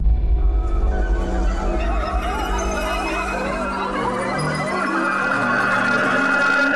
Stressed Out Alarm - Sound Effect Button